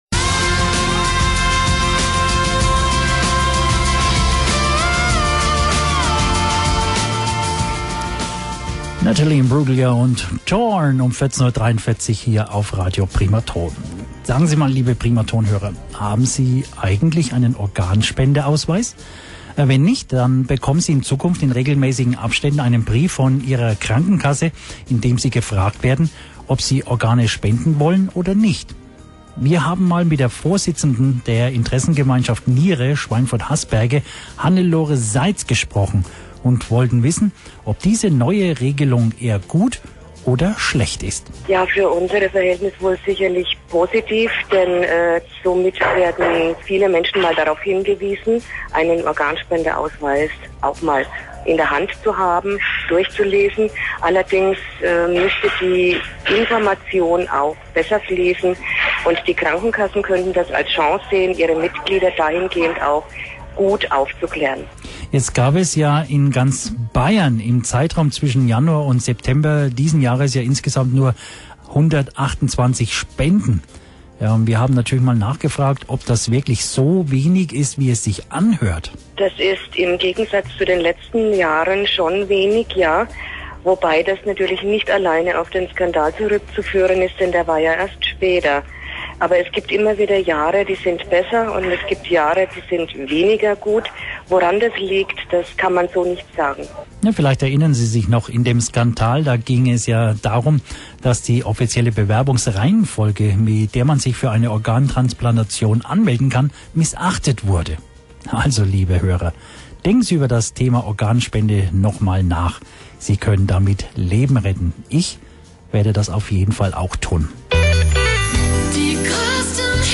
Interview zum Rückgang der Organspenden